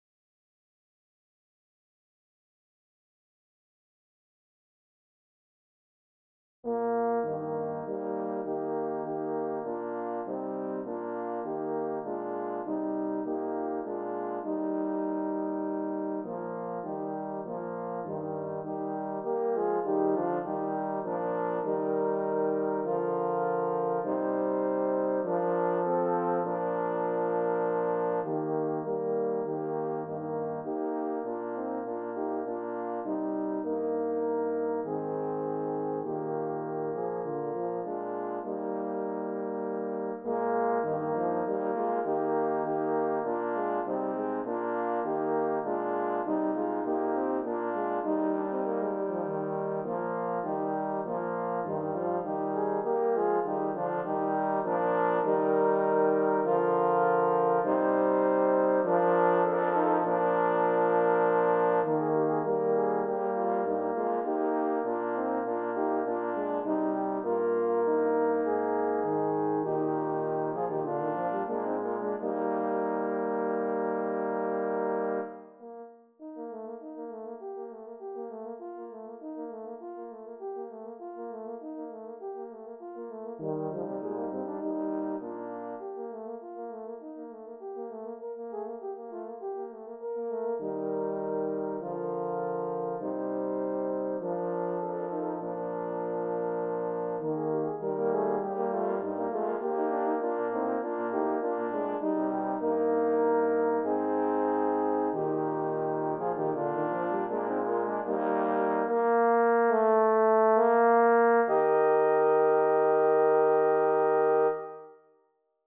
Here is my version of the great old hymn ALL HAIL THE POWER OF JESUS' NAME which I have arranged for horn quartet. I used the FINALE composition program.